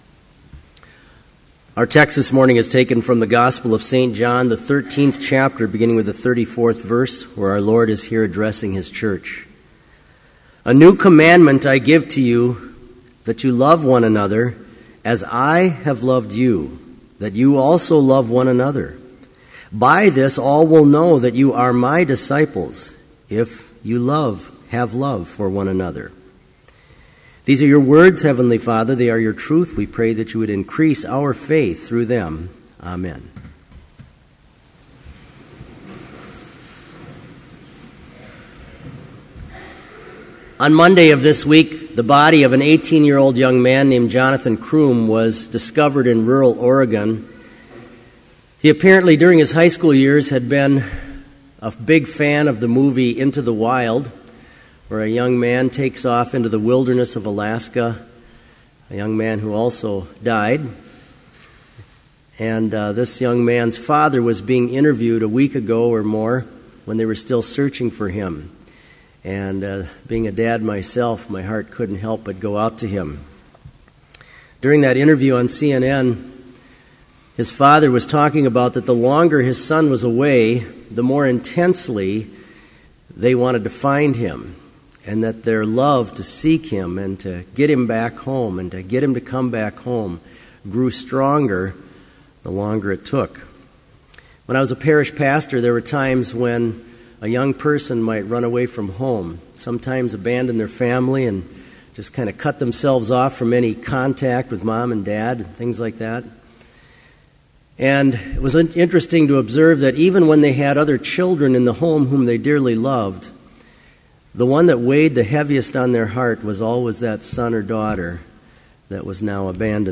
Complete service audio for Chapel - August 30, 2013
Prelude
Homily